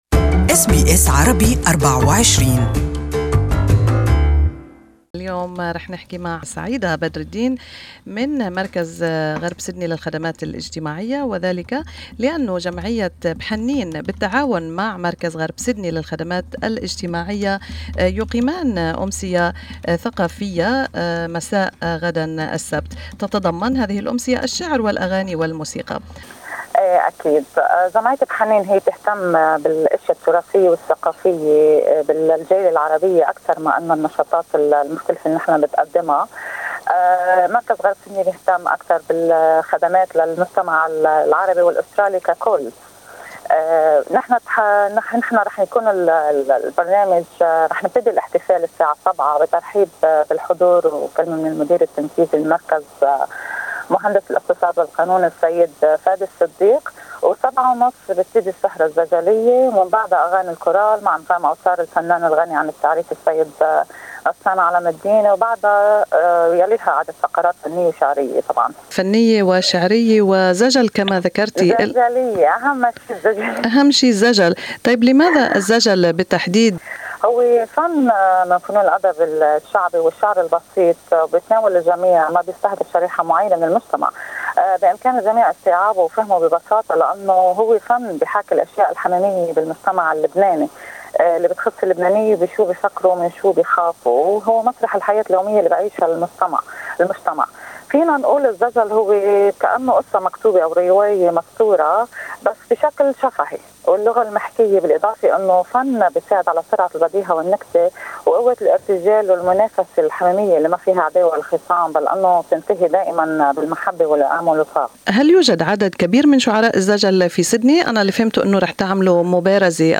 استمعوا الى اللقاء كاملا تحت الشريط الصوتي.